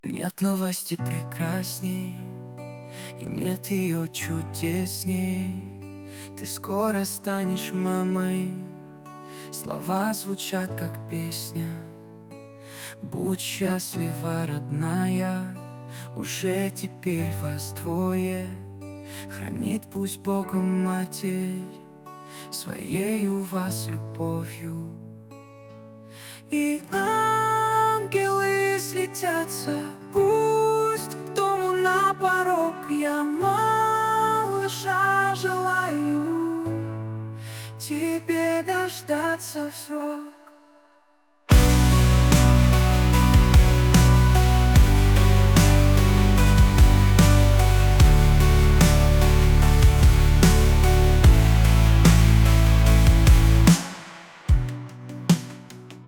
Мелодии на гендер пати, фоновая музыка, песни, демо записи: